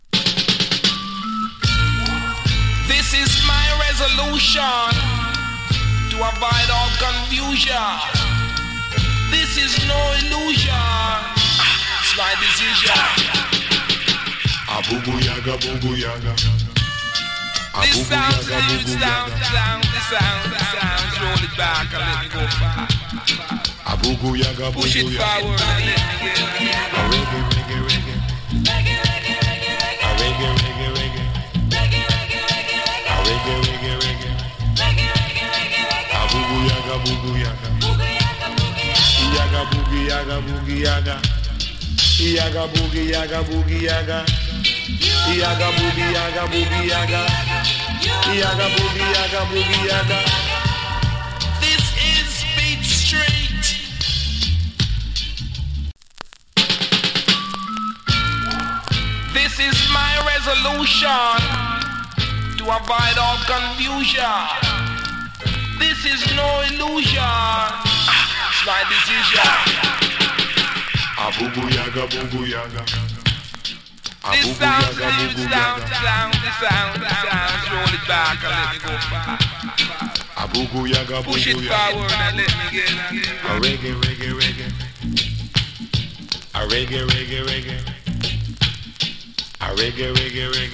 チリ、パチノイズ有り。
INST